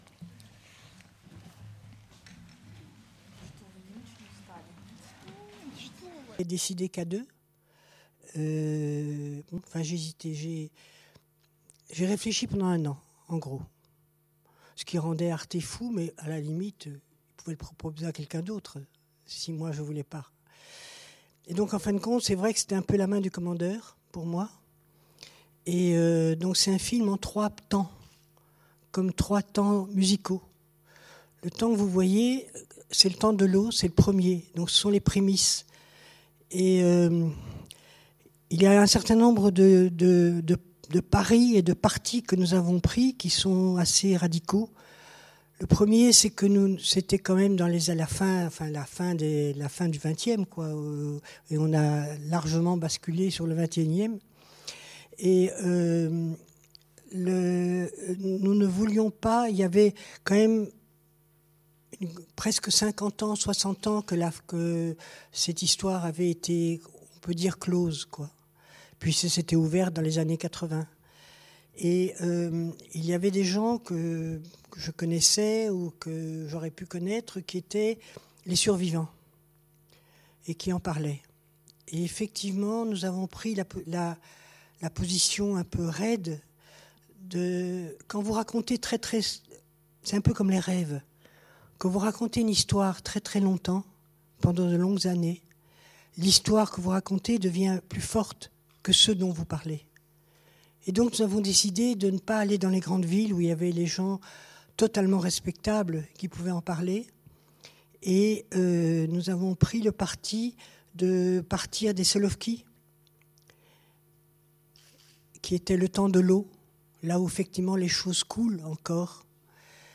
Cette journée d'étude est coordonnée par les missions Action culturelle et bibliothèque numérique de la BULAC et le CERCEC, en partenariat avec RFI et les éditions Autrement, avec le concours de la mission Communication externe de la BULAC.